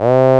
VOICE C2 F.wav